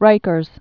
(rīkərz)